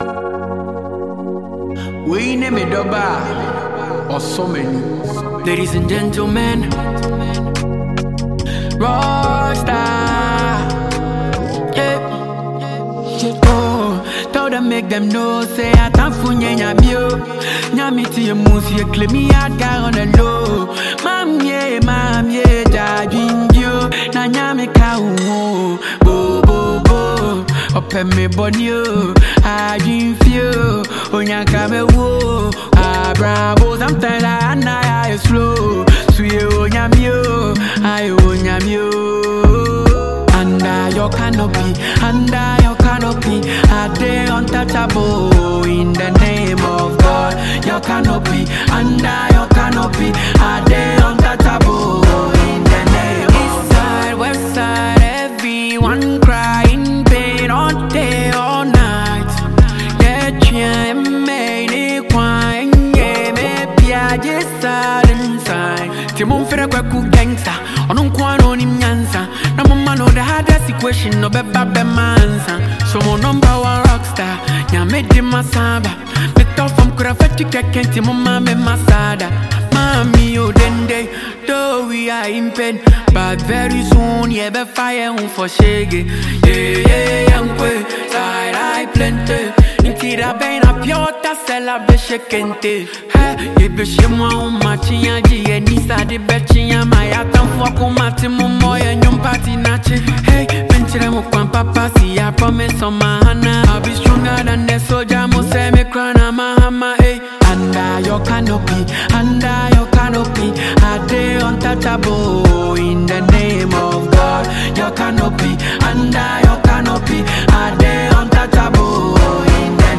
a Ghanaian-based High-life and Afrobeat singer-songwriter